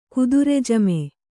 ♪ kudure ka,e